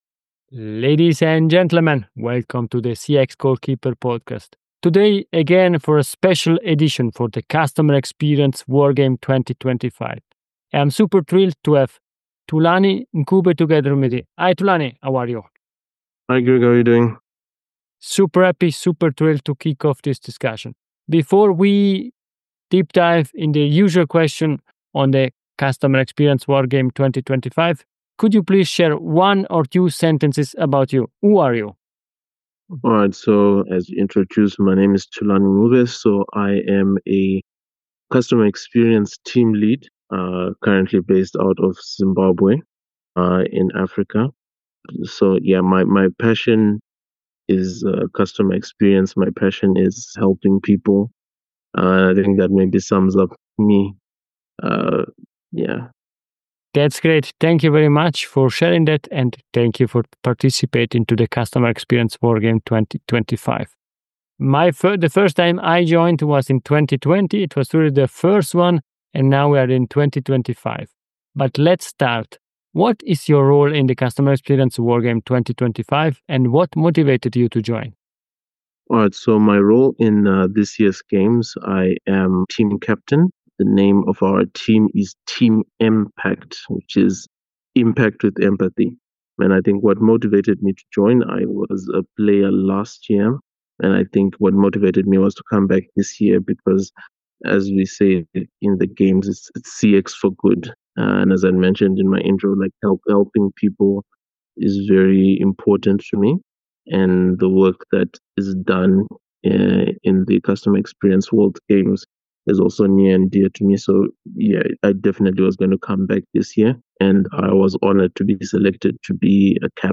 These are brief interviews that share the experiences of outstanding individuals who help charities improve.